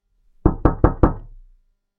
Door Knocking
Three firm knocks on a solid wooden door with natural hand impact and resonance
door-knocking.mp3